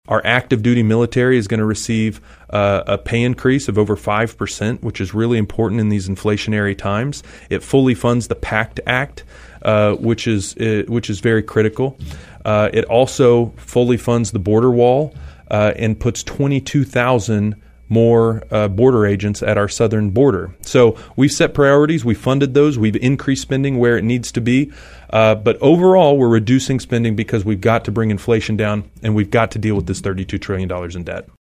On an interview airing Thursday on KVOE, LaTurner said the $16 billion increase will have several positive impacts for veterans.